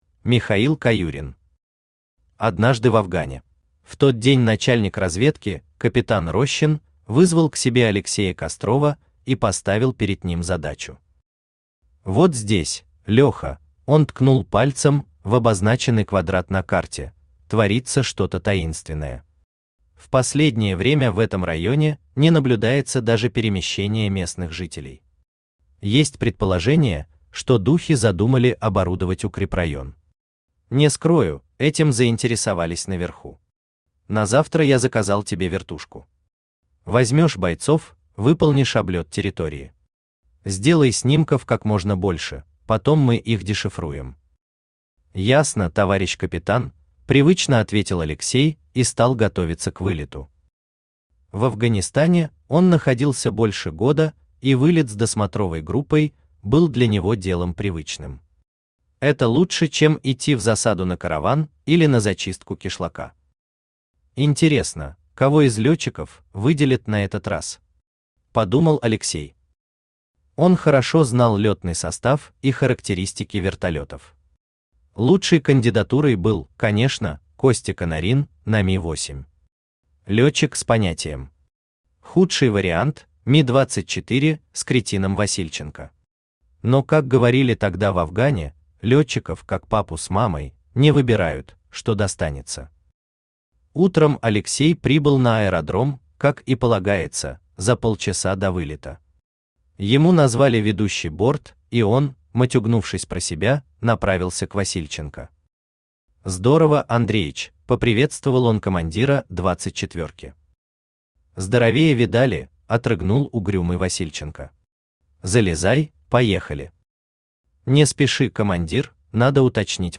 Аудиокнига Однажды в Афгане…
Автор Михаил Александрович Каюрин Читает аудиокнигу Авточтец ЛитРес.